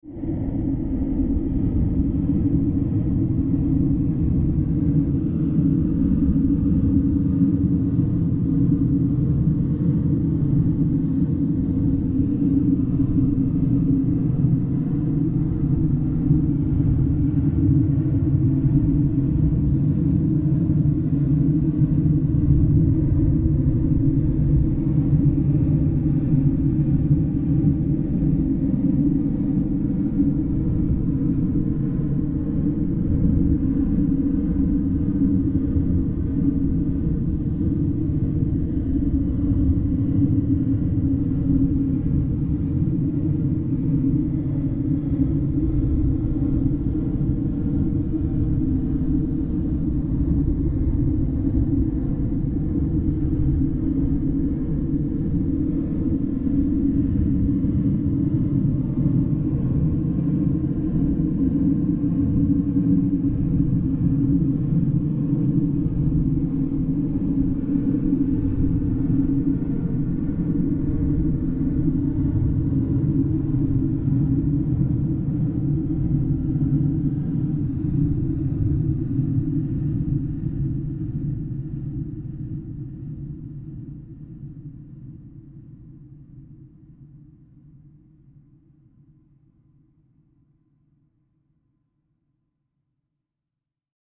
Extra Long Sound Effect - 1m 34s
Use This Extra Long Premium Hollywood Studio Quality Sound In Stereo.
Channels: 2 (Stereo)
This Premium Quality Futuristic Sound Effect
Tags: long large alien beam beams future futuristic laser sci-fi science fiction scifi
sfx sound sound effect sound fx soundfx stolting space spacecraft spaceships